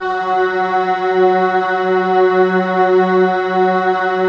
Index of /90_sSampleCDs/Club-50 - Foundations Roland/VOX_xScats_Choir/VOX_xSyn Choir 1